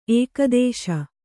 ♪ ēkadēśa